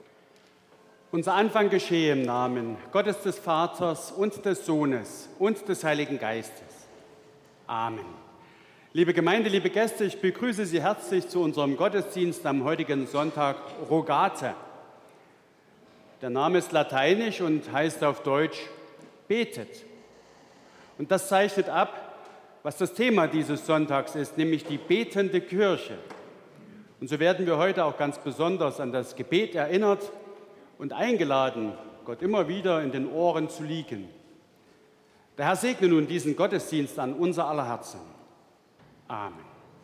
Gottesdienst am 14.05.2023
Audiomitschnitt unseres Gottesdienstes am Sonntag Rogate 2023.